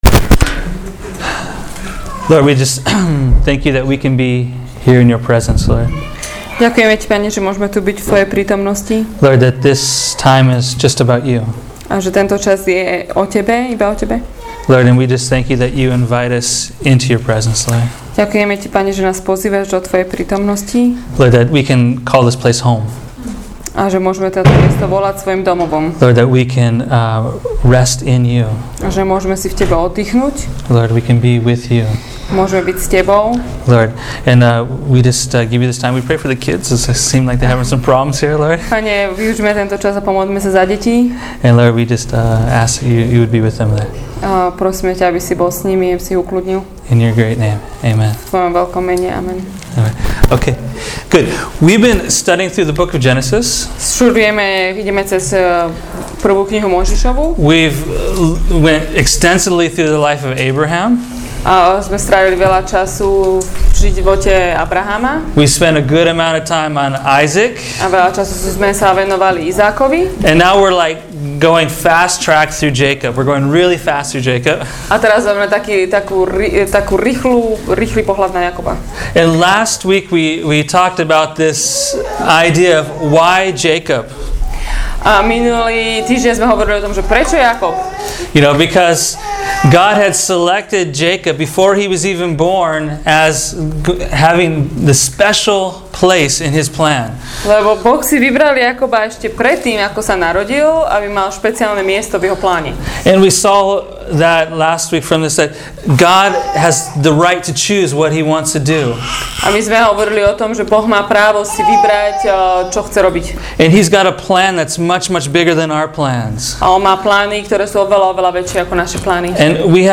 Listen to this teaching from Gen 28-33 – “Finding Jacob”